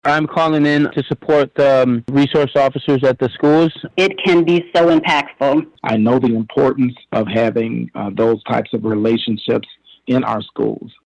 But during last night’s School Board Meeting, there was overwhelming support from members of the public to keep them.
public-comments-at-kps-meeting.mp3